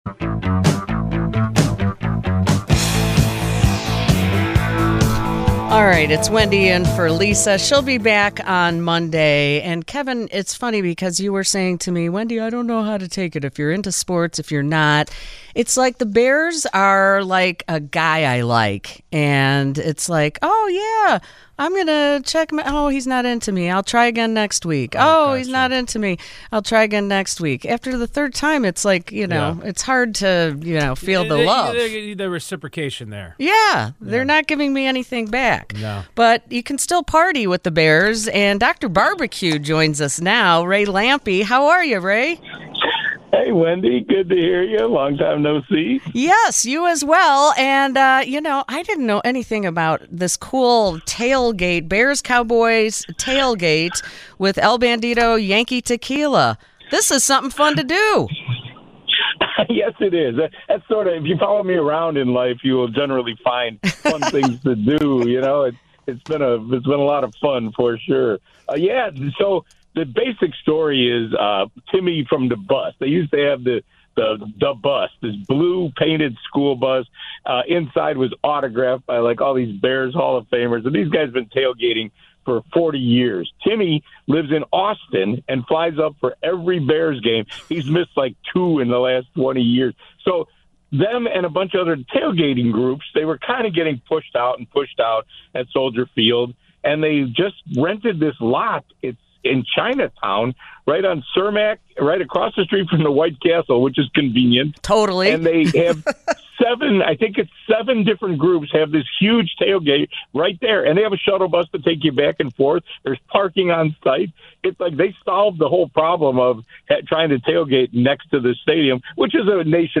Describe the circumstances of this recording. live on WGN Radio